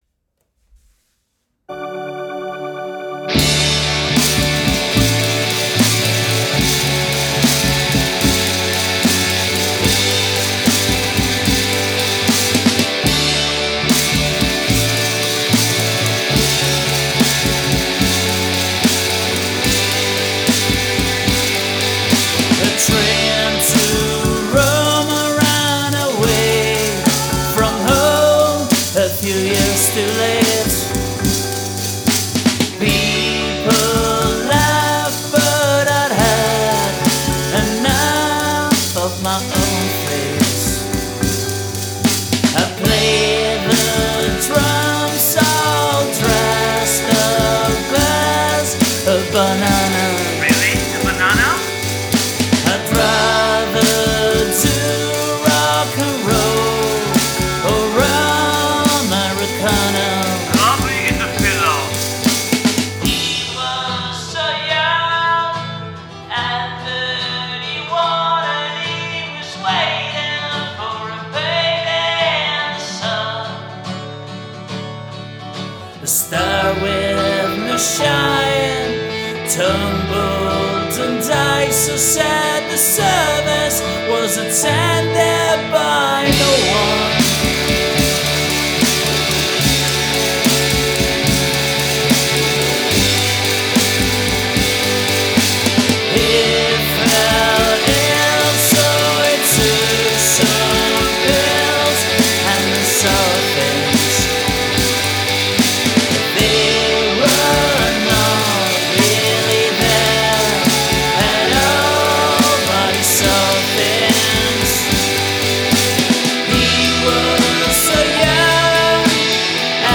vocals, guitars, drums, bass, keyboards